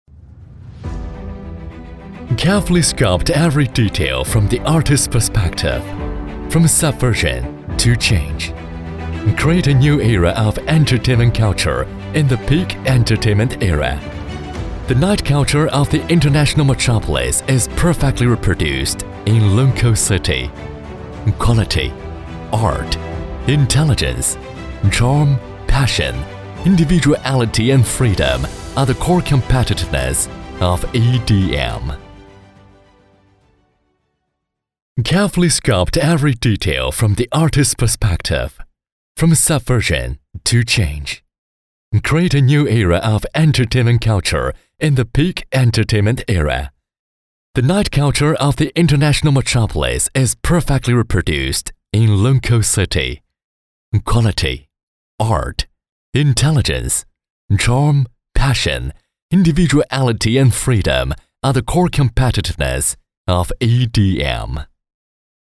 男43号配音师
声音庄重、浑厚、大气，擅长中英双语。
英文-男43-【大气沉稳】.mp3